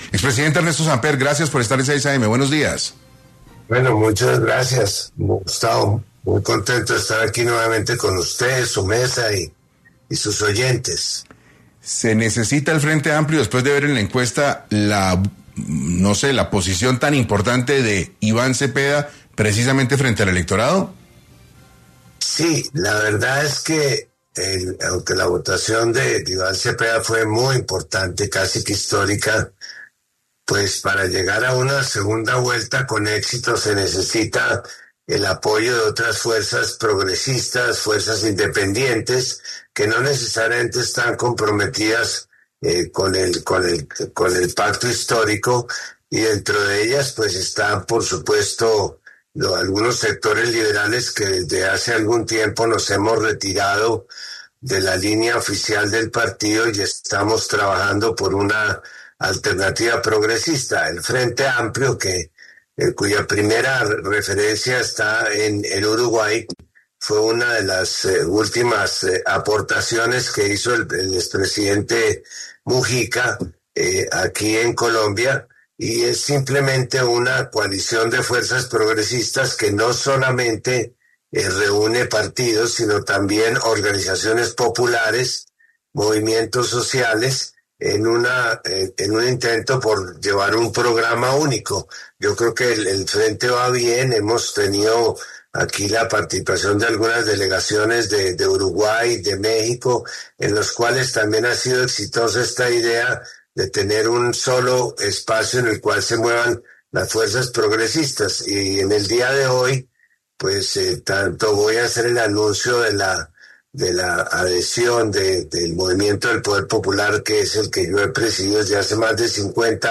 En esa instancia, el expresidente Ernesto Samper, una de las figuras llamativas en el campo político, habló en 6AM.